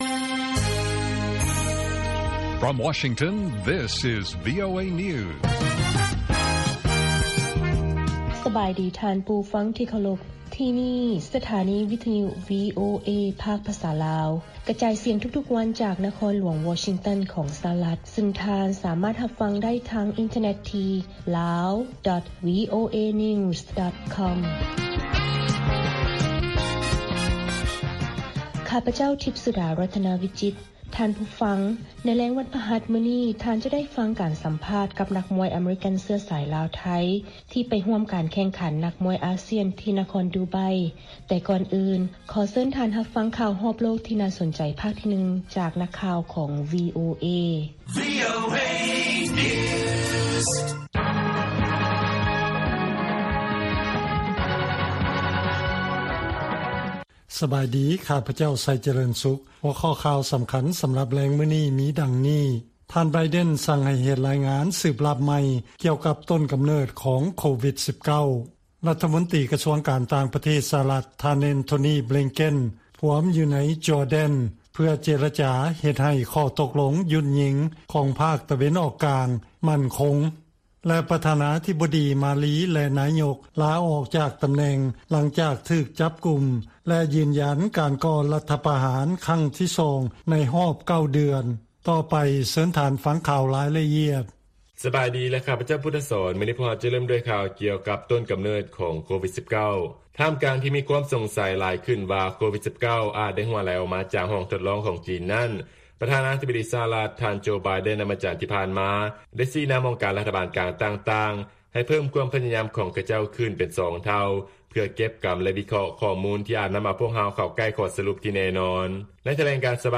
ລາຍການກະຈາຍສຽງຂອງວີໂອເອລາວ: ທ່ານ ໄບເດັນ ສັ່ງໃຫ້ເຮັດ ລາຍງານສືບລັບໃໝ່ກ່ຽວກັບຕົ້ນກຳເນີດຂອງ COVID-19
ວີໂອເອພາກພາສາລາວ ກະຈາຍສຽງທຸກໆວັນ.